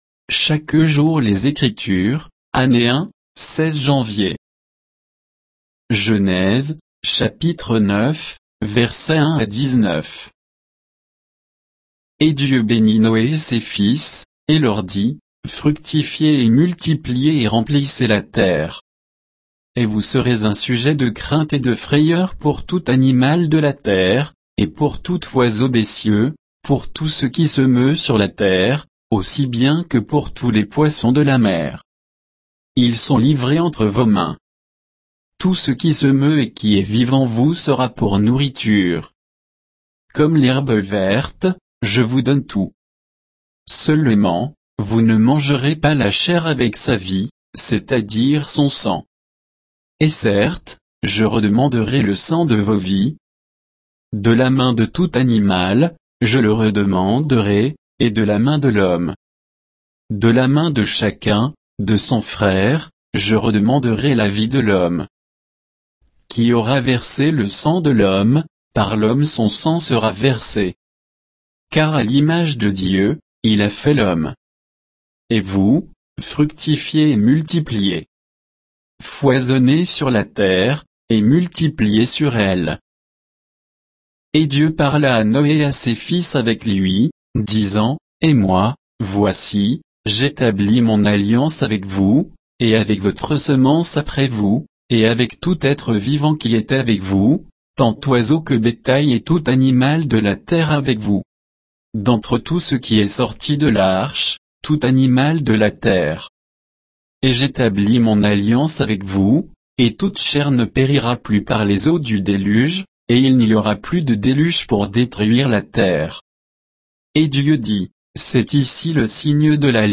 Méditation quoditienne de Chaque jour les Écritures sur Genèse 9